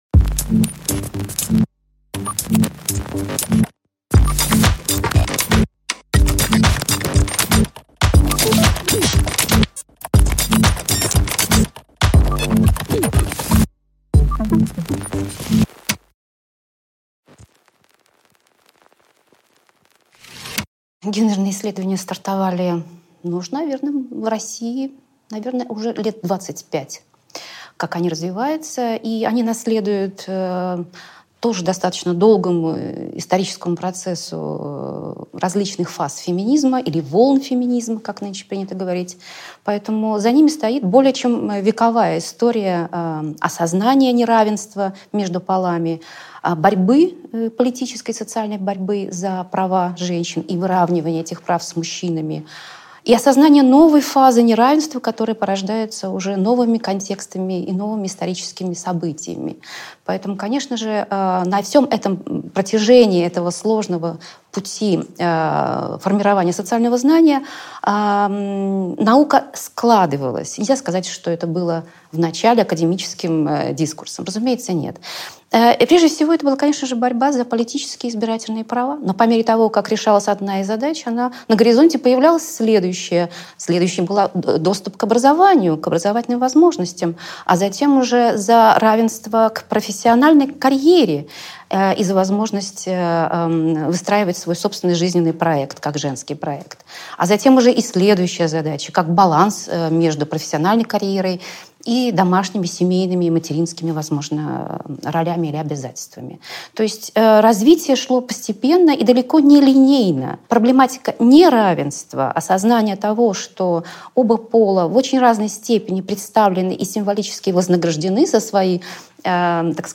Аудиокнига Проектирование пола | Библиотека аудиокниг